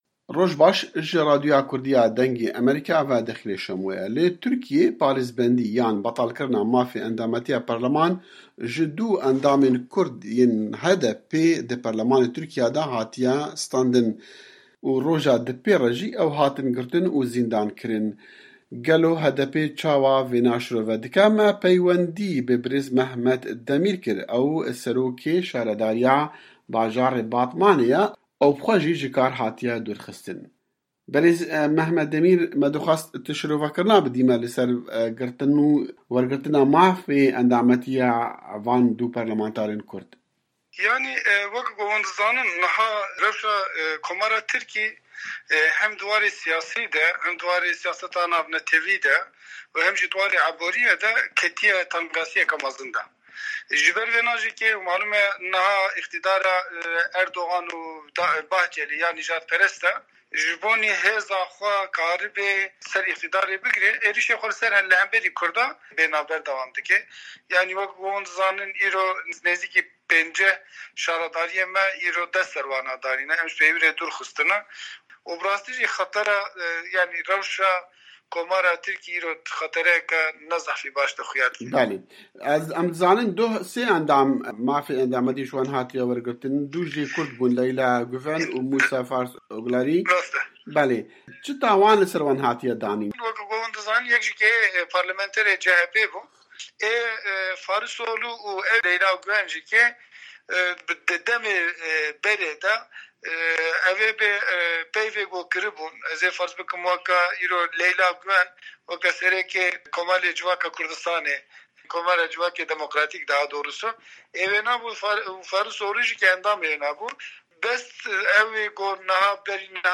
Hevpeyvîn_Mehmet_Demir